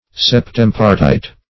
Search Result for " septempartite" : The Collaborative International Dictionary of English v.0.48: Septempartite \Sep*tem"par*tite\, a. [L. septem seven + E. partite.]
septempartite.mp3